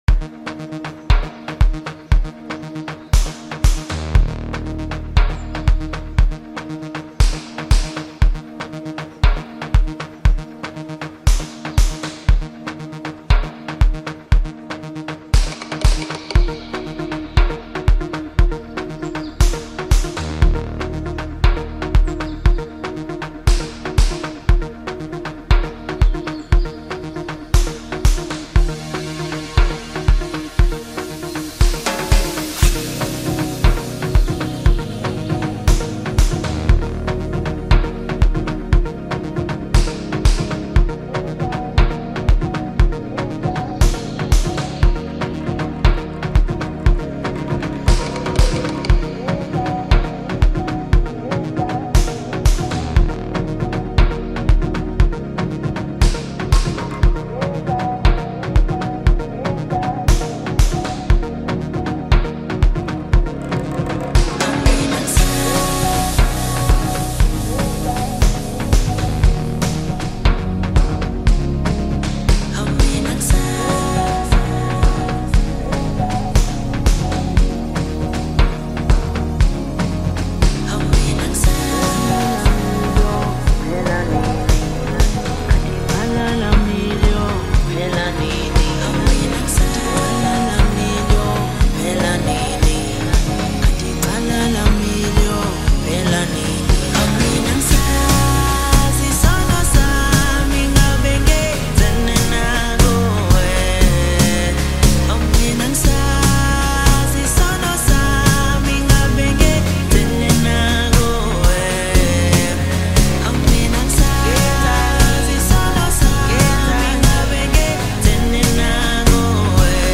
Home » Amapiano » Deep House » Hip Hop » Latest Mix